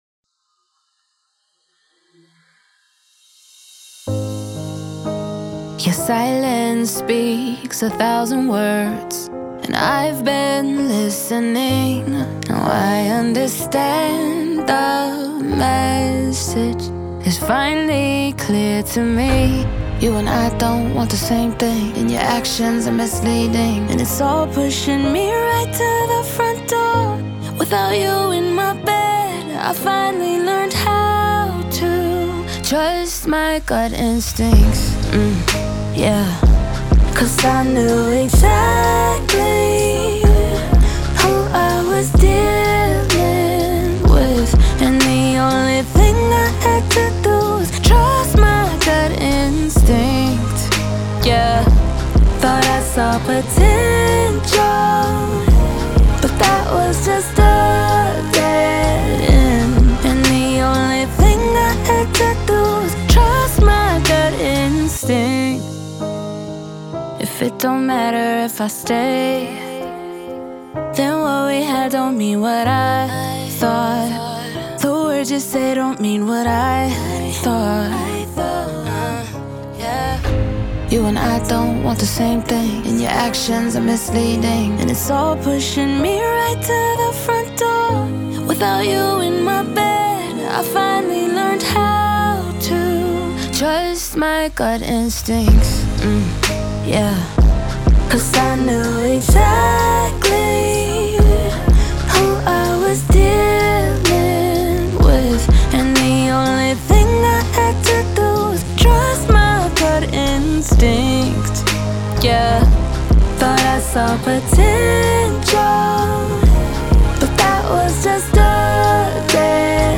Pop, R&B
F Min